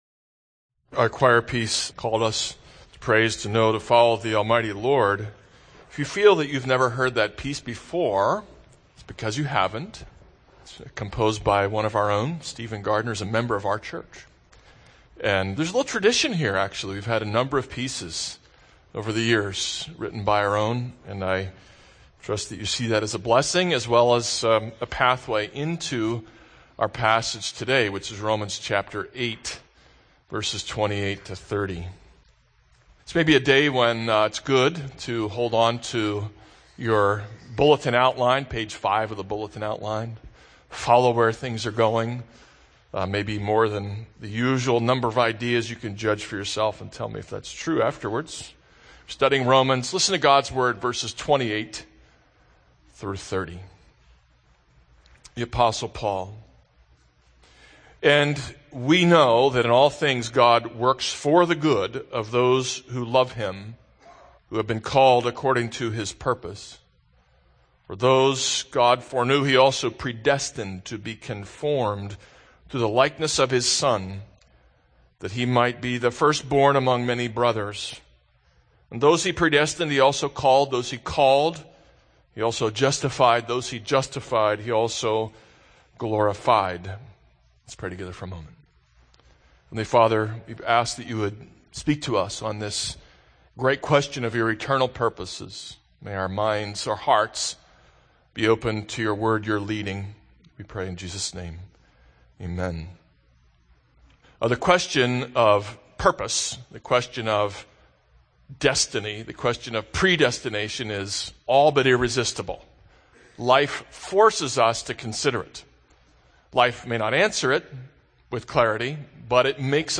This is a sermon on Romans 8:28-30.